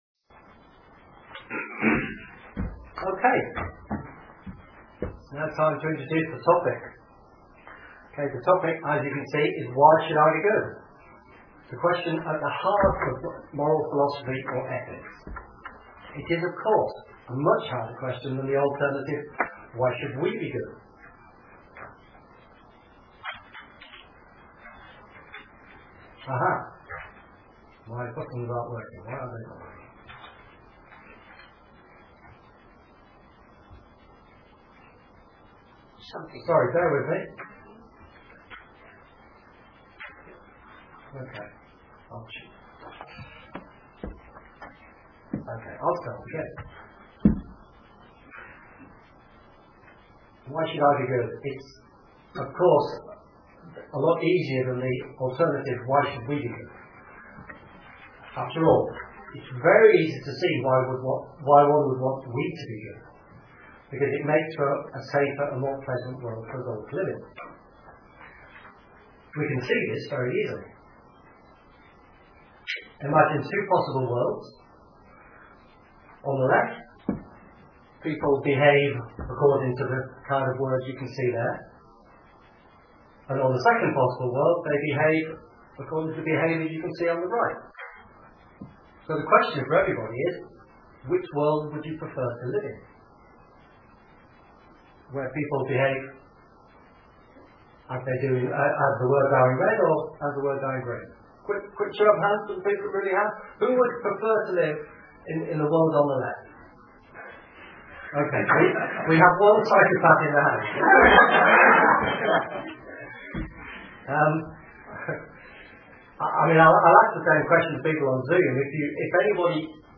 Seven speakers tackled what is perhaps the most fundamental question in Ethics and Moral Philosophy.